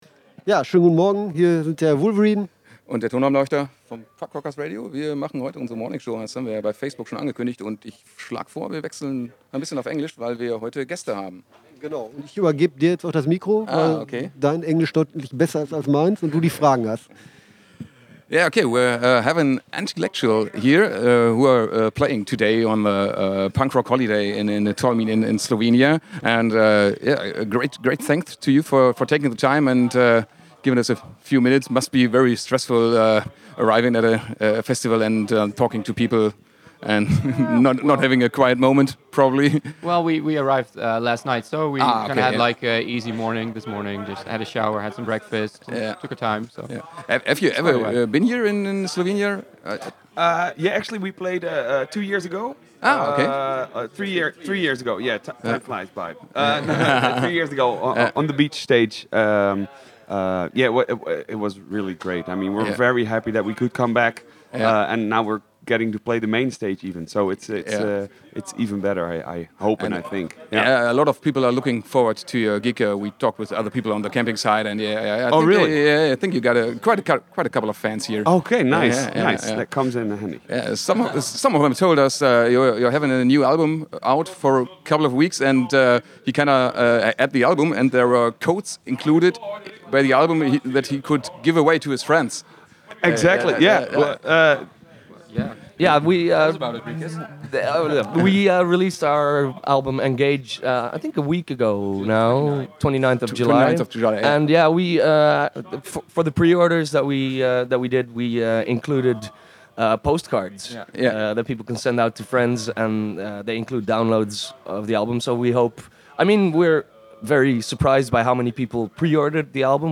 Interview mit Antillecual beim Punk Rock Holiday 1.6 Interview with Antillectual at Punk Rock Holiday 1.6
interview-with-antillectual-at-punk-rock-holiday-mmp.mp3